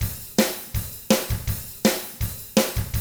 164ROCK T6-R.wav